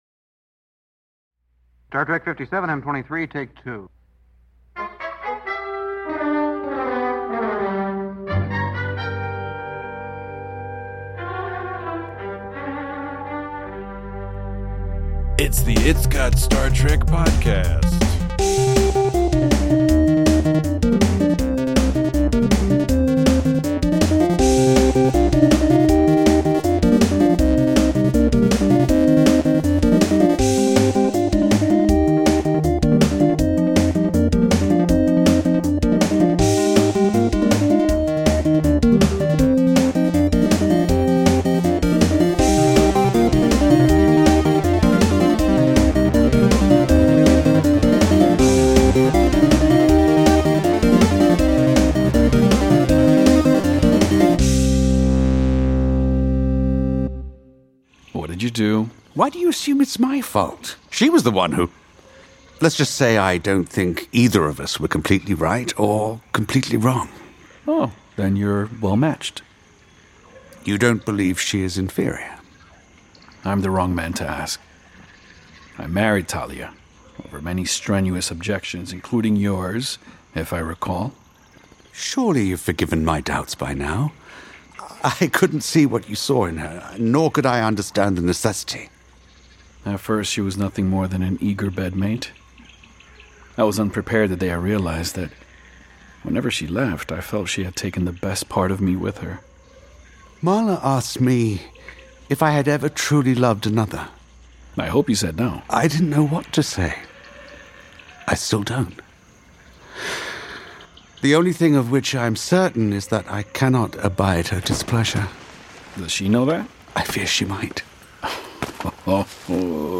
Death and romance intermingle on Ceti Alpha 5. Join your boar-baiting hosts as they discuss death, canoodling, and a heck of a lot of kickass nerdiness in this gripping installment of the Star Trek: Khan podcast! Also, we play our version of the "Muffin Mouth" game with a "Star Trek" twist, respond to a few voicemails, and go on several unrelated but thoroughly entertaining tangents.